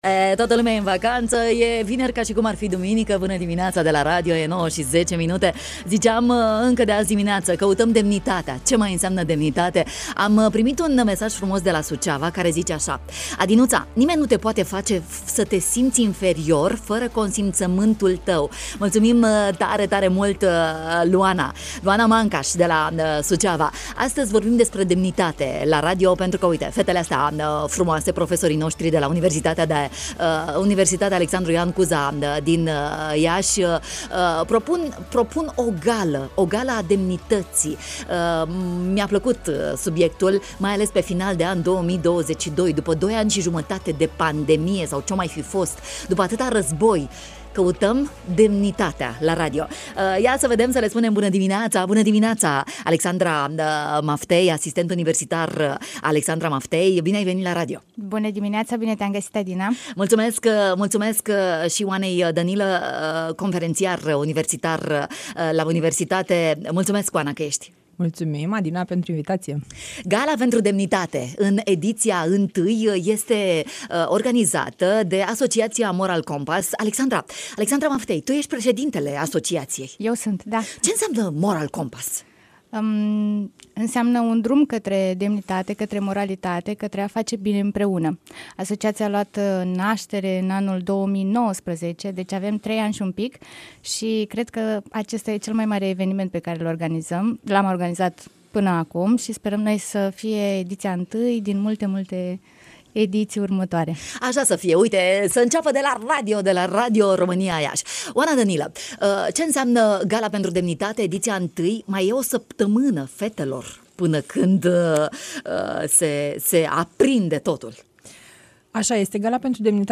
în direct în studio la matinalul de la Radio România Iași